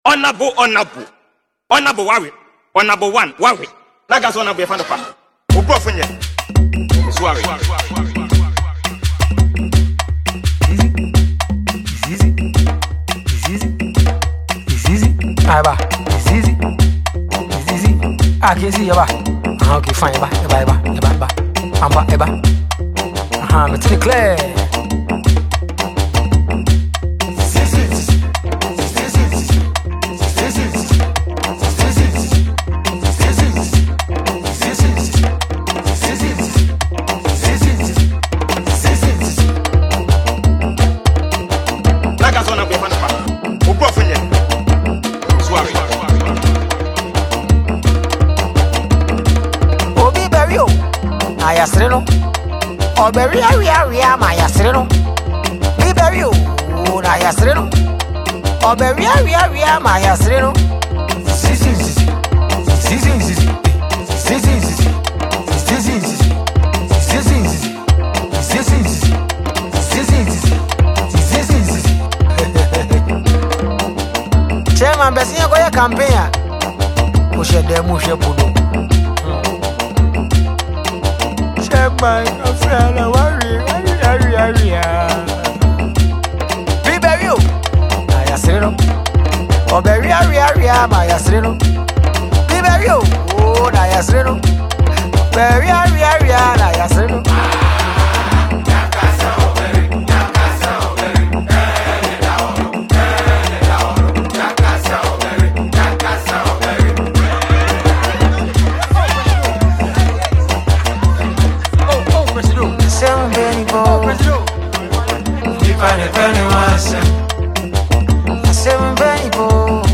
a Ghanaian highlife artiste
danceable jam